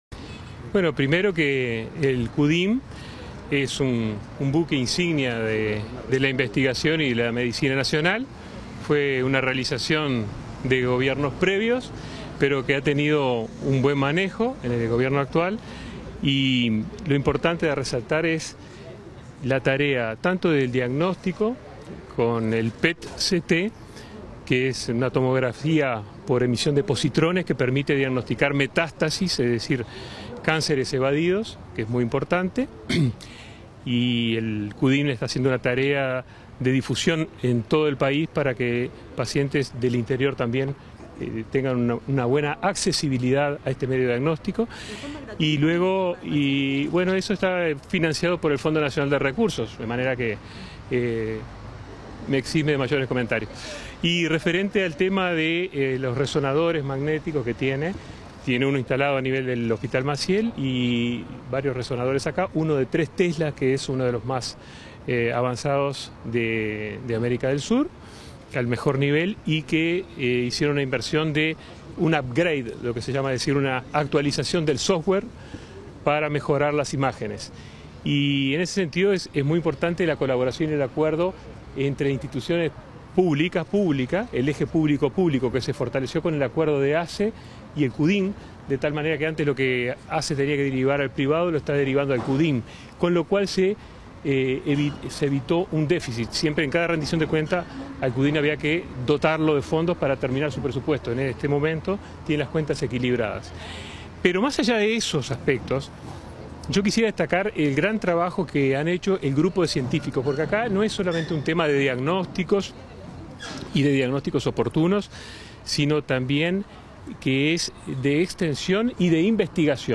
Declaraciones a la prensa del ministro de Salud Pública, Daniel Salinas
Declaraciones a la prensa del ministro de Salud Pública, Daniel Salinas 17/08/2022 Compartir Facebook X Copiar enlace WhatsApp LinkedIn Tras la visita a las instalaciones del Centro Uruguayo de Imagenología Molecular (Cudim), este 17 de agosto, el ministro de Salud Pública, Daniel Salinas, realizó declaraciones a la prensa.